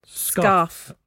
[scAHf]